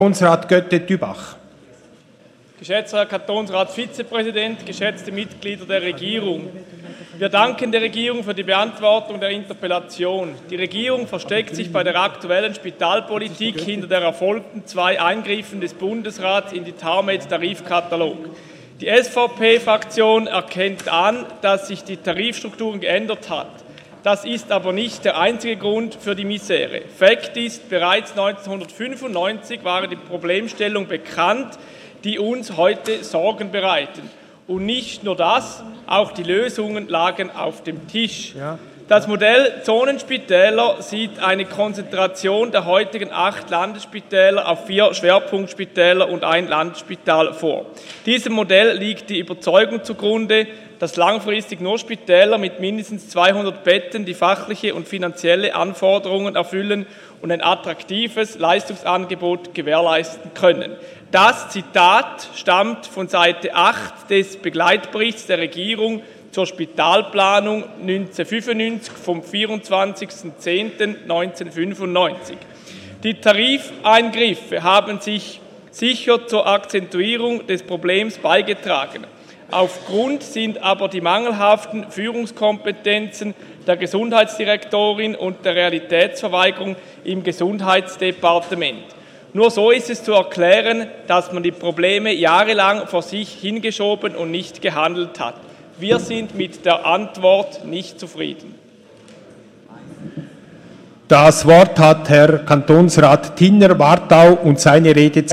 27.11.2018Wortmeldung
Session des Kantonsrates vom 26. bis 28. November 2018